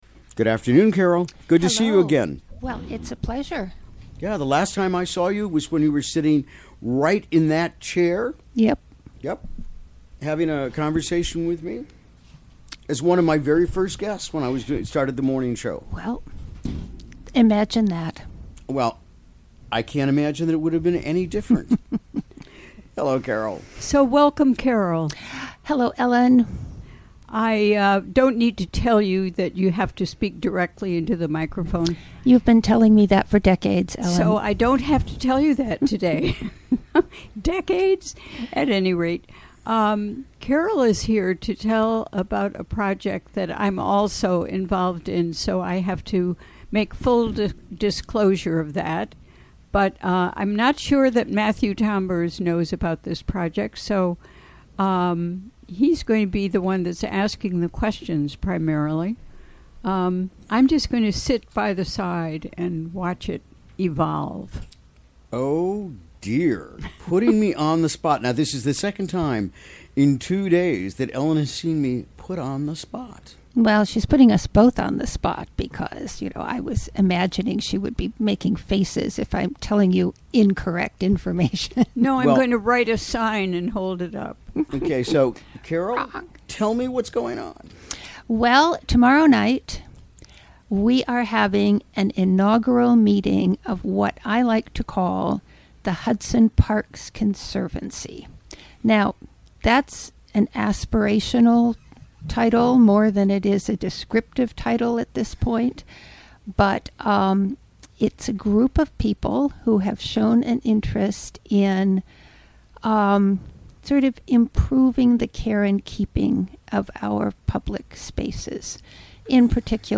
Recorded during the WGXC Afternoon Show on October 19, 2017.